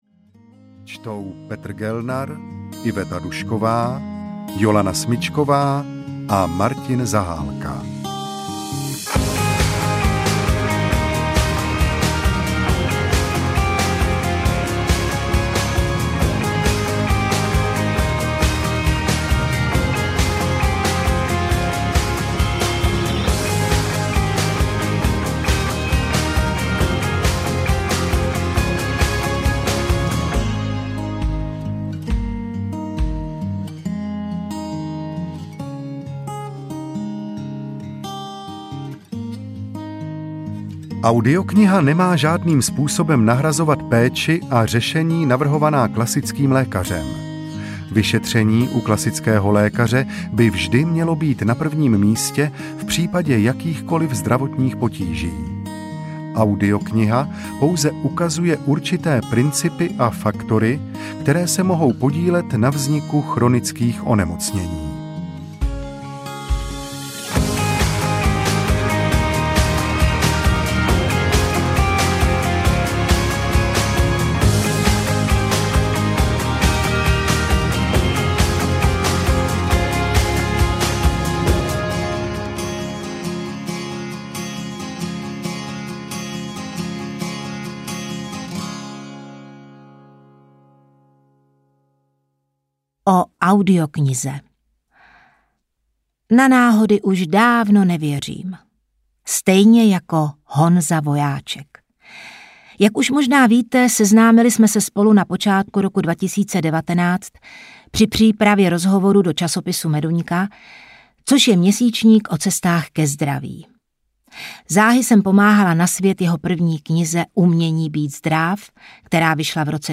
Rozhodni se být zdráv audiokniha
Ukázka z knihy